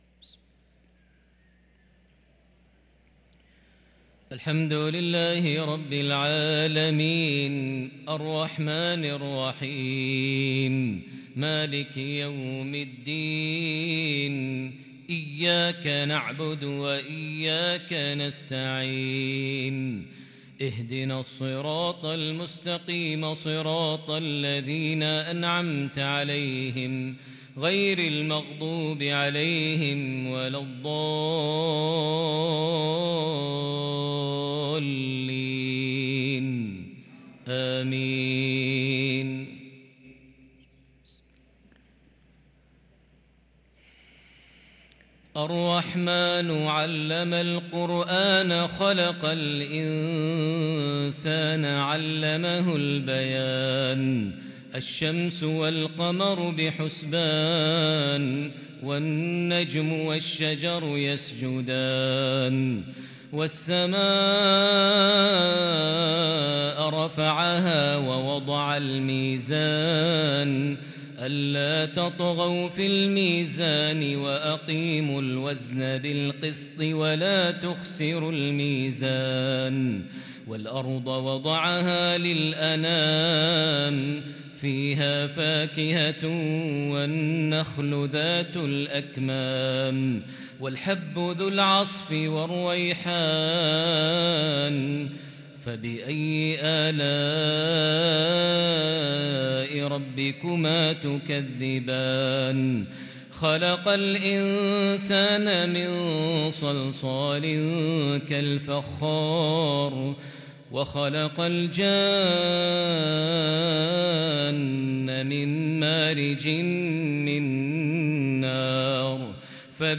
صلاة العشاء للقارئ ماهر المعيقلي 26 جمادي الأول 1444 هـ
تِلَاوَات الْحَرَمَيْن .